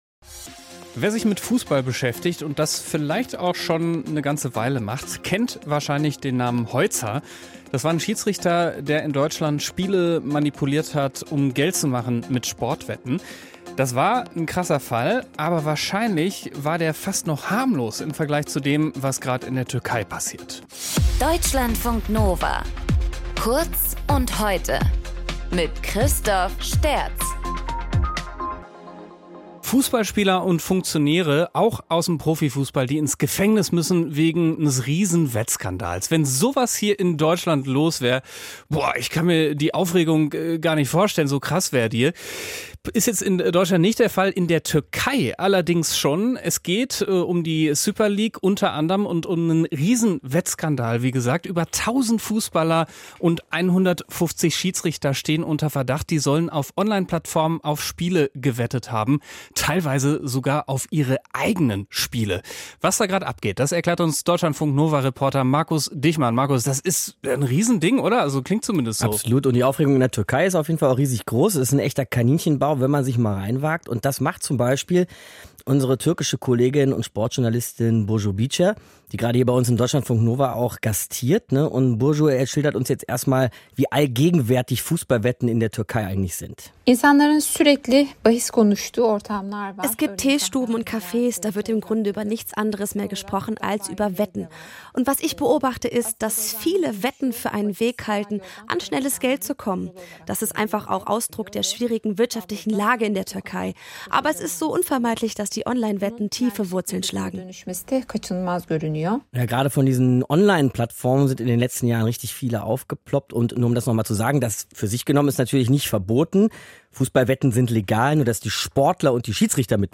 Gesprächspartner